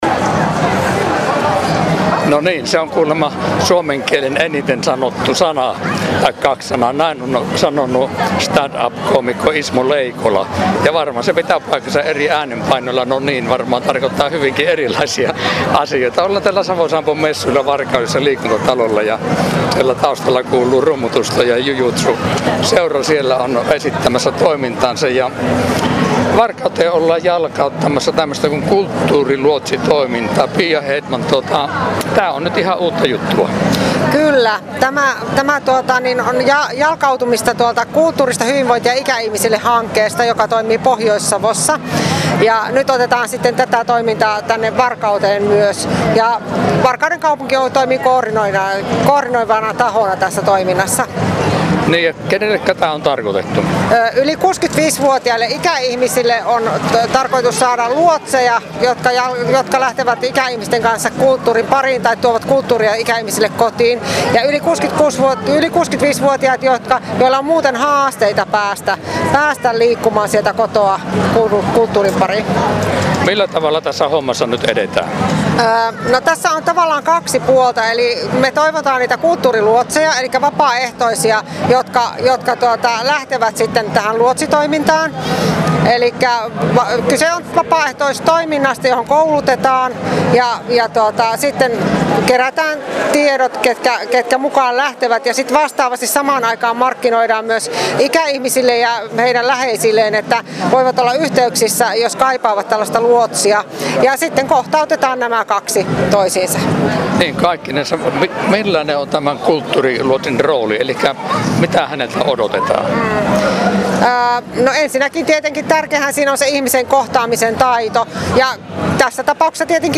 Varkauden kaupungin osastolla esiteltiin kulttuuriluotsitoimintaa, joka on vertaistoiminnan periaatteisiin pohjautuvaa osallistavaa vapaaehtoistyötä. Toiminta edistää kulttuurin saavutettavuutta ja osallisuutta heille, jotka eivät pääse kulttuurin pariin ilman tukea ja seuraa. Äänihaastattelussa